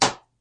马桶盖关闭1
描述：在带有Android平板电脑的浴室中录制，并使用Audacity进行编辑。
标签： 小便 管道 冲洗 冲洗 厕所 船尾 漏极 浴室 厕所 盥洗室
声道立体声